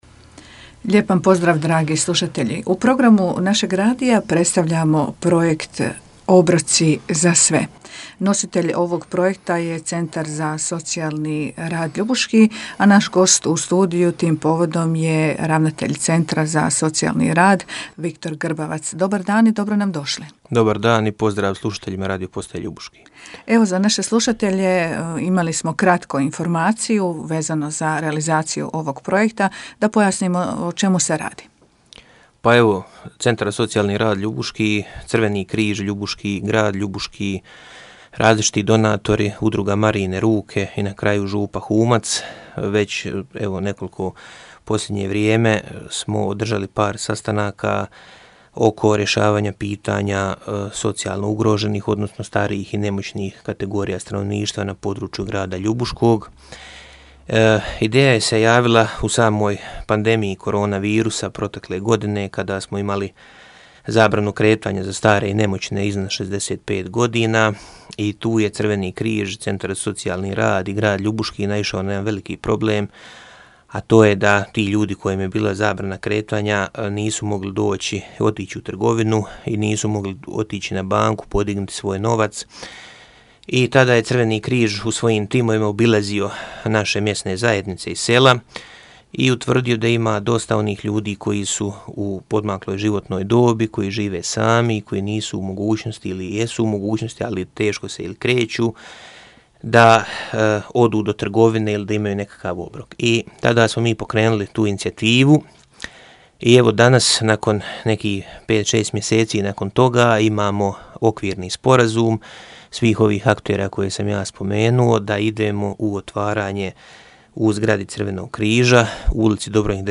u razgovoru za Radio Ljubuški